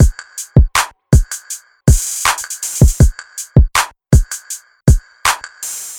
• 80 bpm trap drum loop C.wav
Hard trap drum loop with bass drum tuned in C
80_bpm_trap_drum_loop_C_5iE.wav